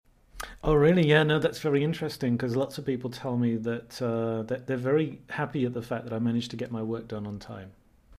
I am playing the role of the candidate. What level of evidence do you hear?